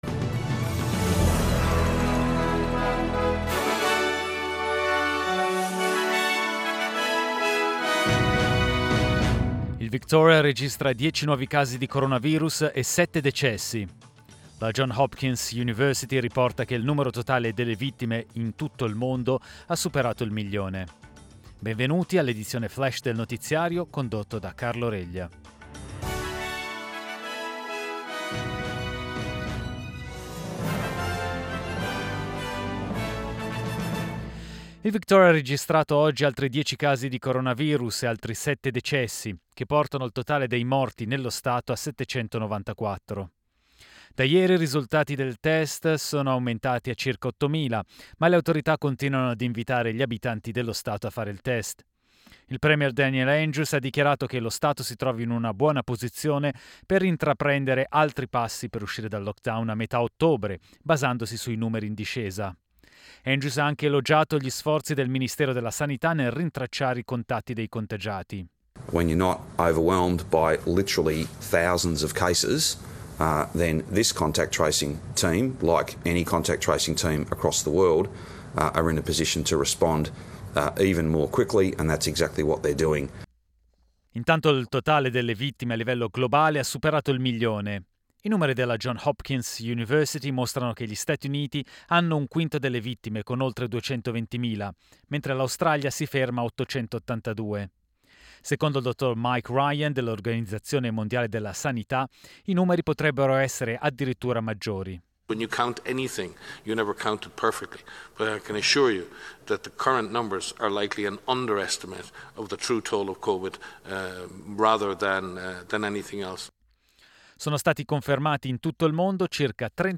Italian News Flash martedì 29 settembre